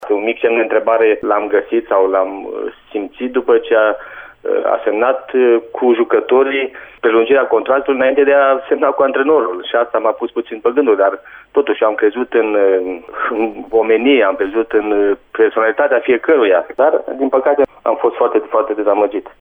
Dorinel Munteanu a vorbit, pentru Radio Reșița, după despărțirea de CSM Reșița, anunțată la finele săptămânii trecute.